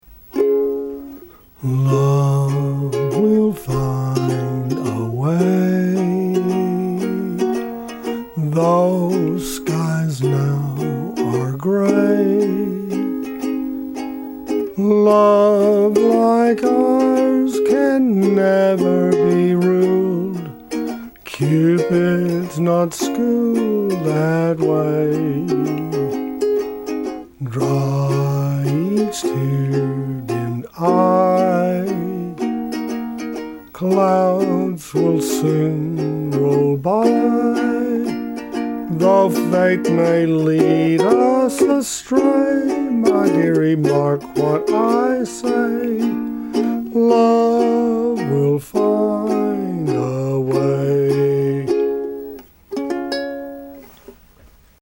Ukulele mp3 songs from sheet music
Please ignore any sour notes.